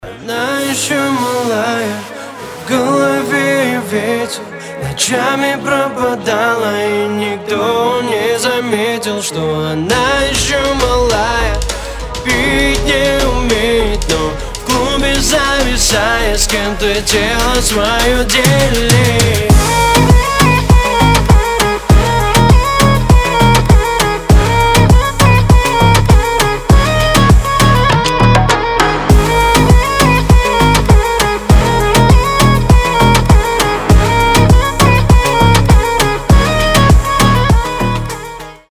• Качество: 320, Stereo
поп
восточные мотивы
Хип-хоп
красивая мелодия